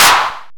Clap
ED Claps 23.wav